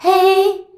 HEY     G.wav